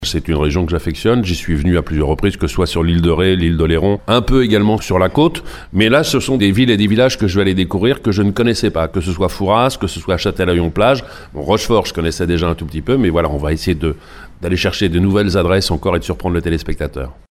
Nous avions rencontré à cette occasion l’animateur Stéphane Thébaut qui promettait de belles surprises aux téléspectateurs :